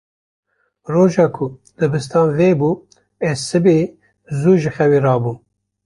Pronúnciase como (IPA)
/zuː/